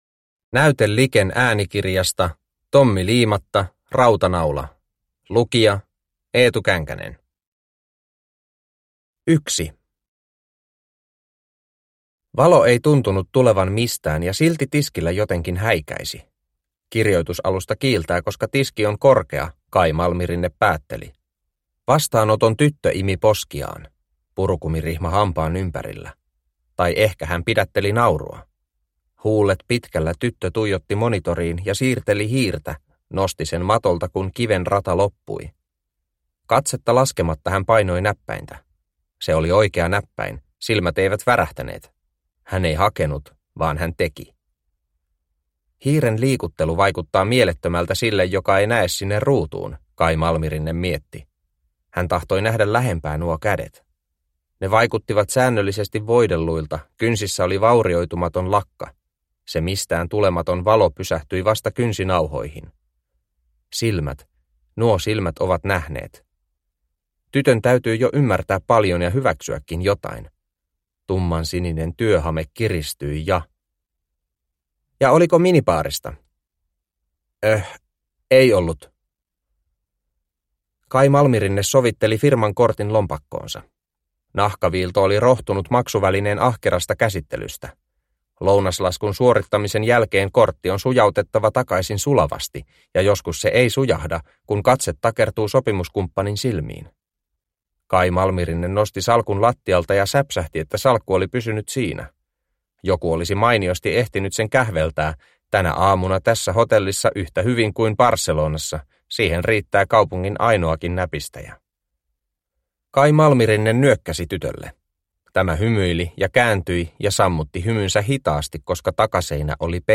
Rautanaula – Ljudbok – Laddas ner